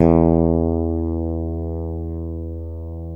Index of /90_sSampleCDs/East Collexion - Bass S3000/Partition A/FRETLESS-A